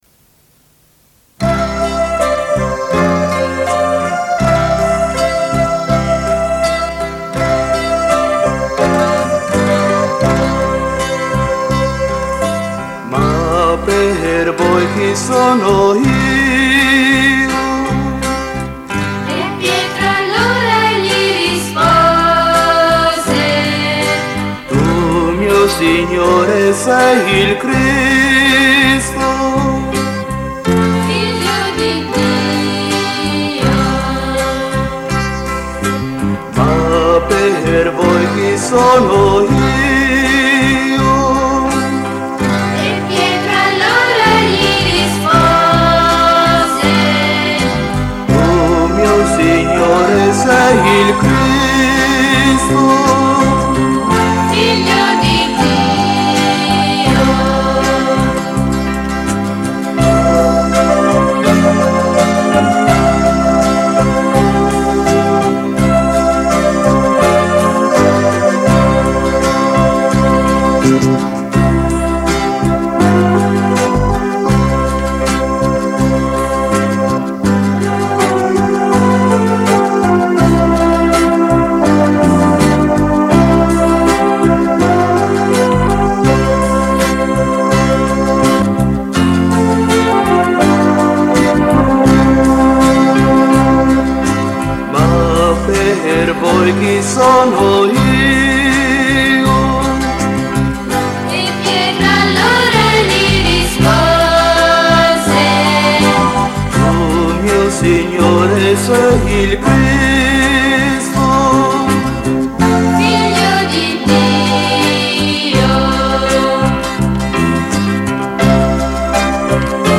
Canto per la Decina di Rosario e Parola di Dio